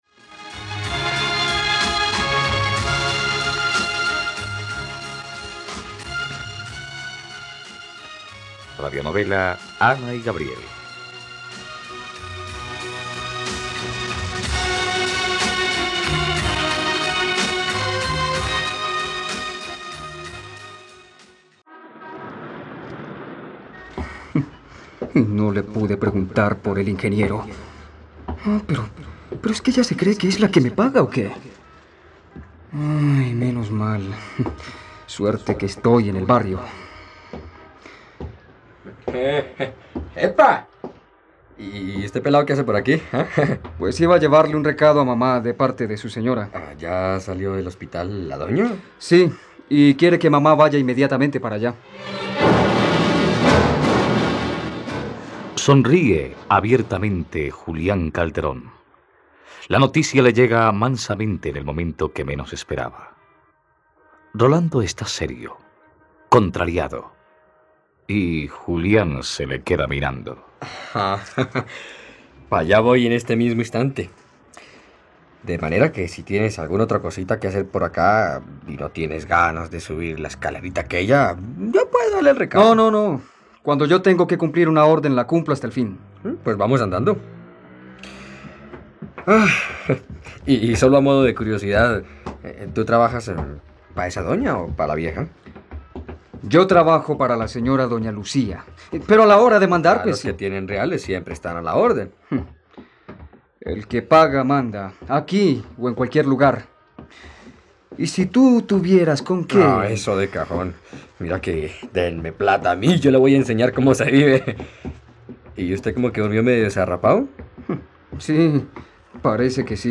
Ana y Gabriel - Radionovela, capítulo 67 | RTVCPlay